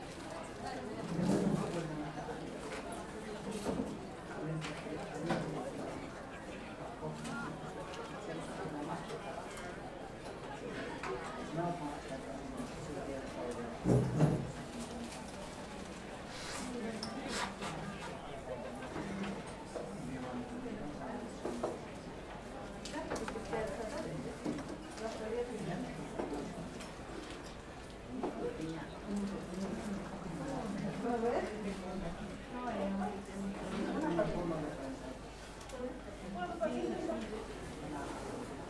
Sakura Melody - Club Ambience
The same thing came to our mind but because we handle the music and ambient sounds separately, we go with the pure sounds of the club :)
A club without music doesn't quite feel like a club now, does it? :D
Club_Ambience.wav (d)